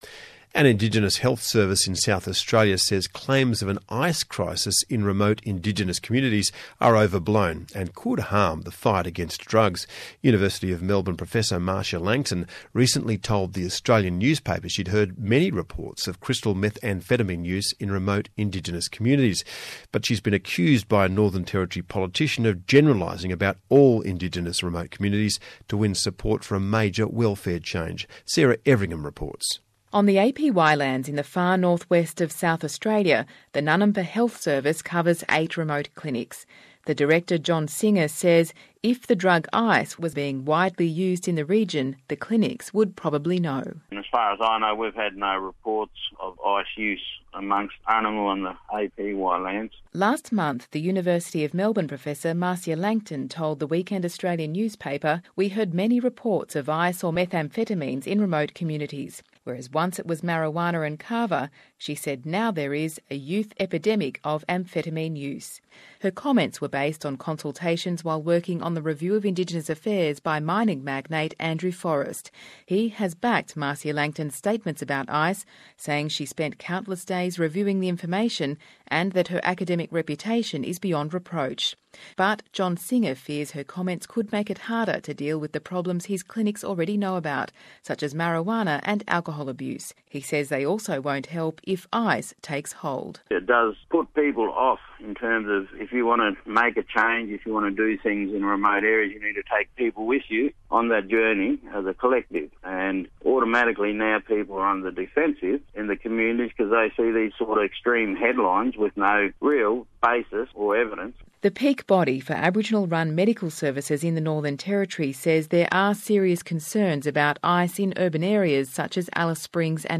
ABC AM AUDIO TRANSCRIPT